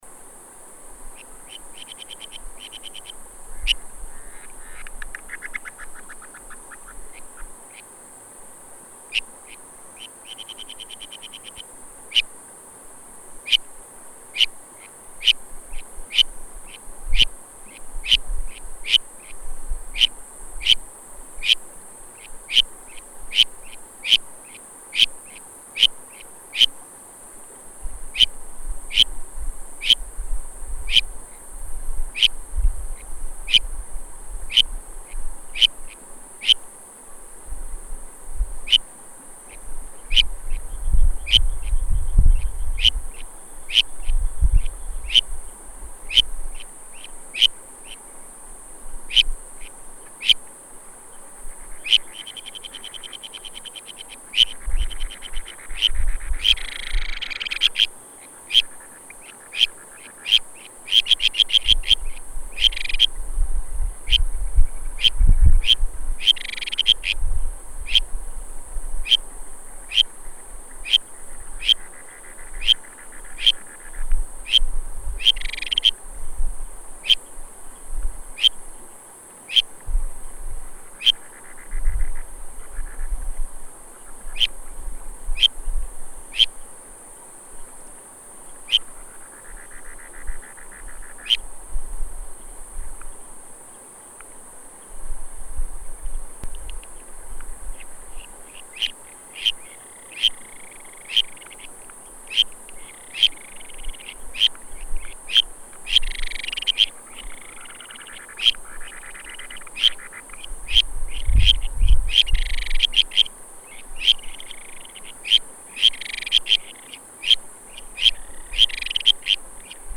日本樹蛙 Buergeria japonica
花蓮縣 壽豐鄉 米亞丸
山區溪流旁，有零星褐樹蛙叫聲
3隻競叫